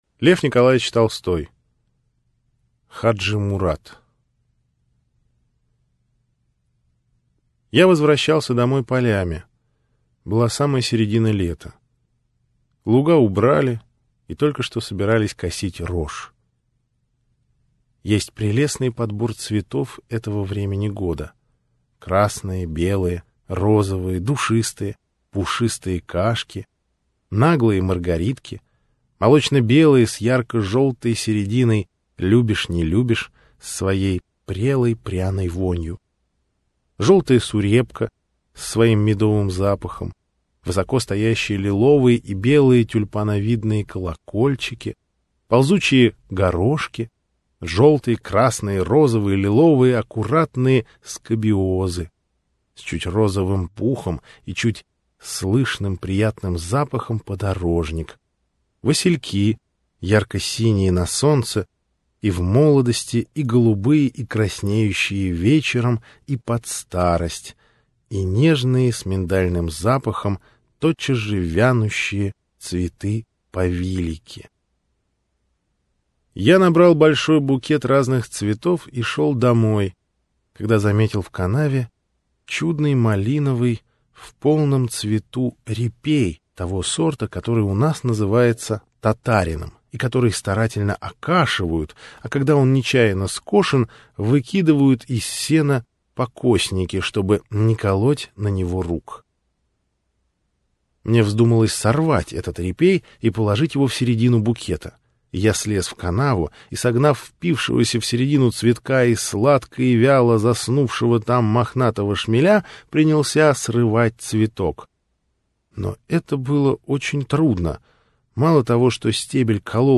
Аудиокнига Хаджи-Мурат - купить, скачать и слушать онлайн | КнигоПоиск